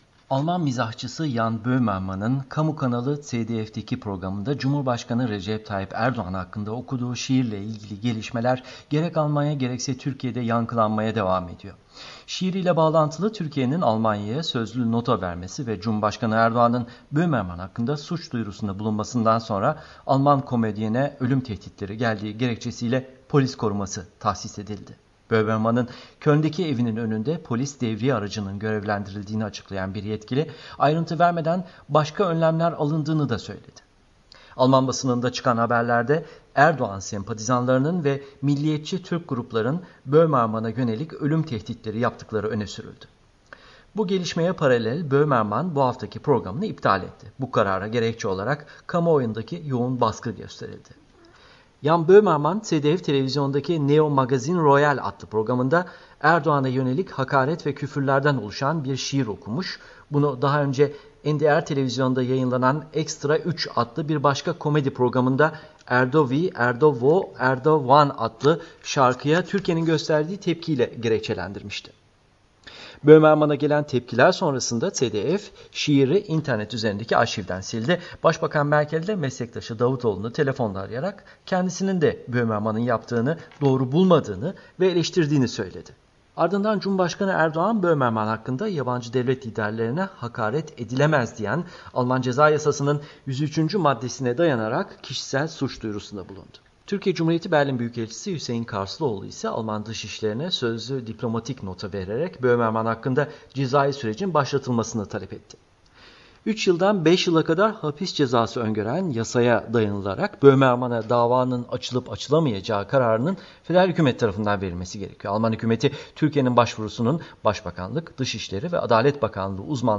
VOA Türkçe - Haberler